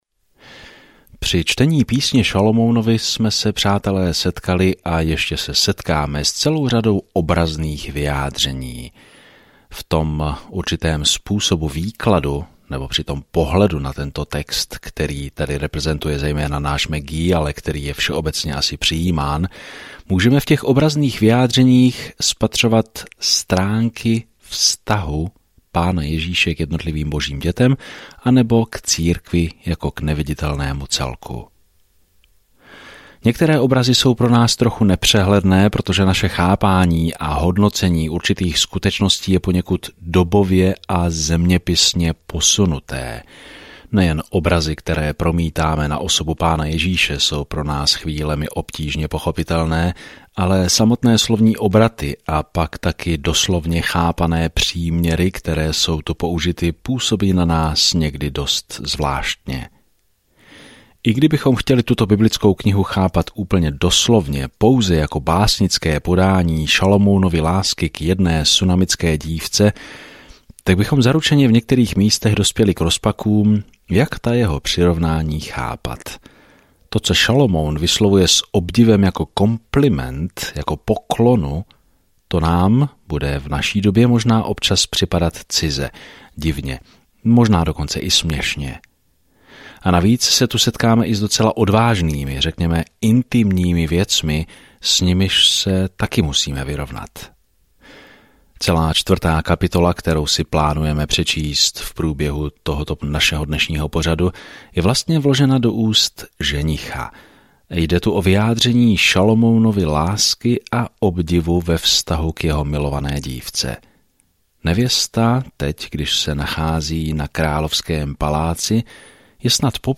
Písmo Píseň 4 Den 7 Začít tento plán Den 9 O tomto plánu Song of Solomon je malá milostná píseň oslavující lásku, touhu a manželství v širokém srovnání s tím, jak nás Bůh poprvé miloval. Denně procházejte Song of Solomon, zatímco budete poslouchat audiostudii a číst vybrané verše z Božího slova.